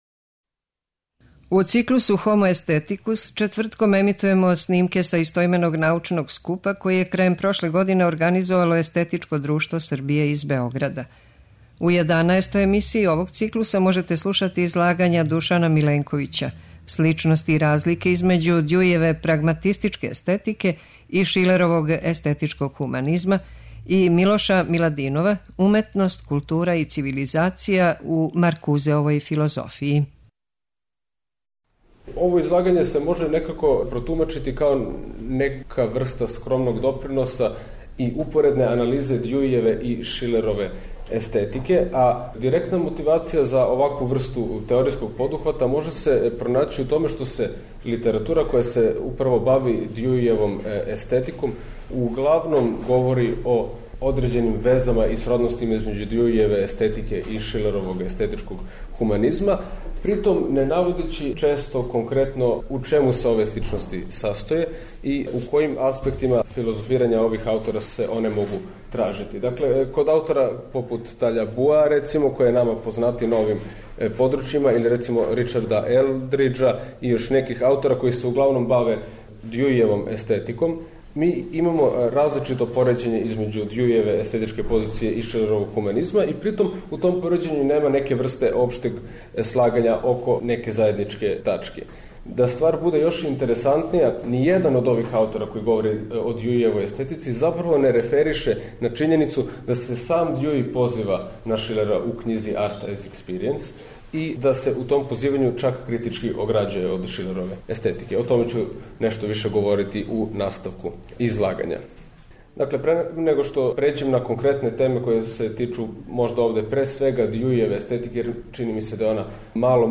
Научни скупoви